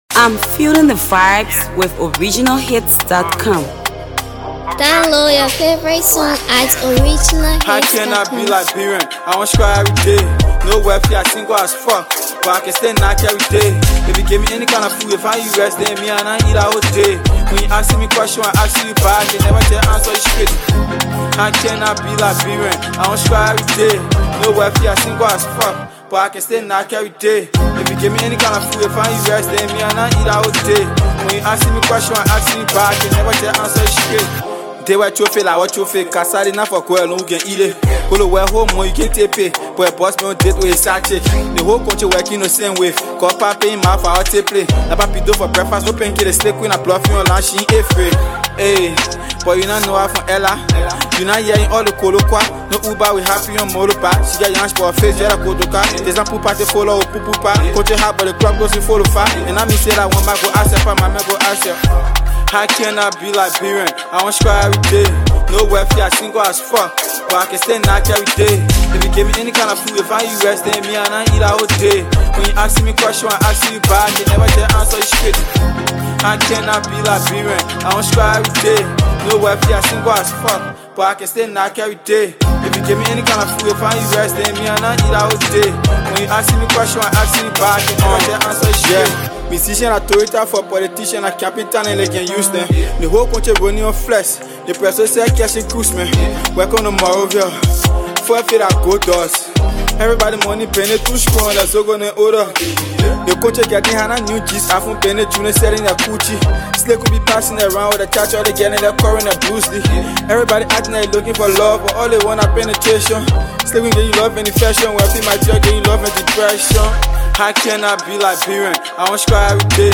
Liberian sensational Hipco rap artist
dubious rap freestyle